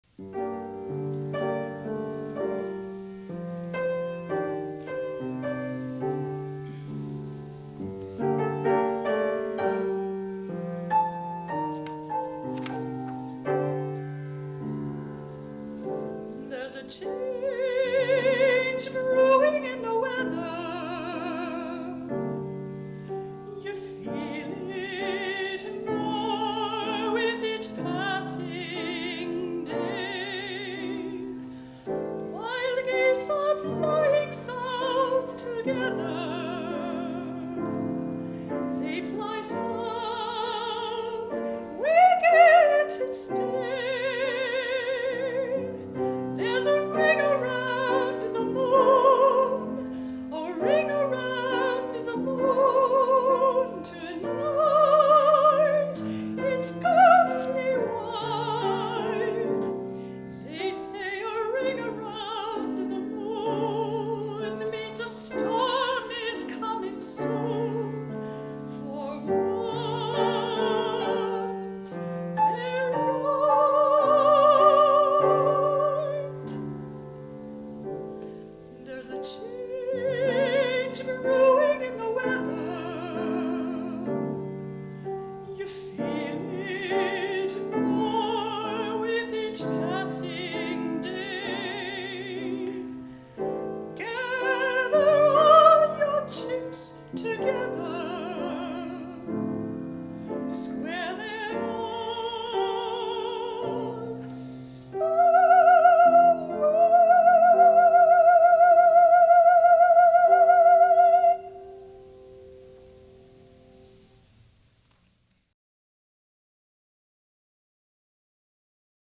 soprano
piano in concert at St. Anselm College